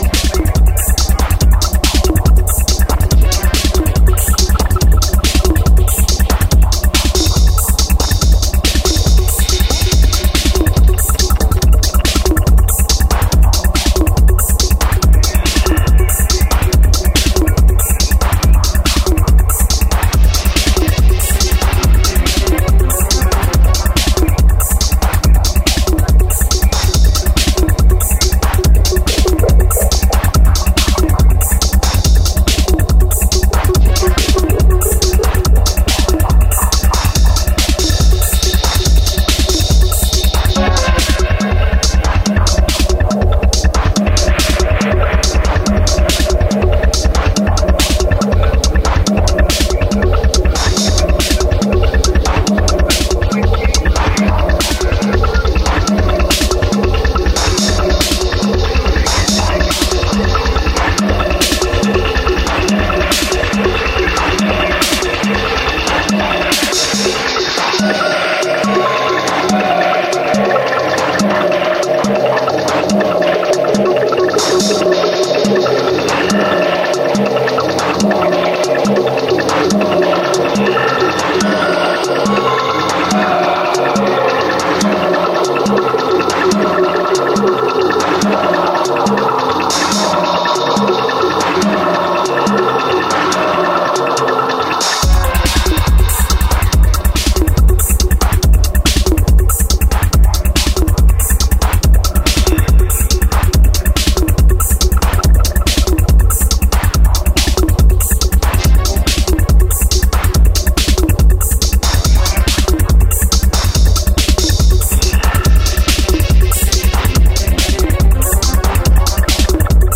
Dark spaced out electro-techno tracks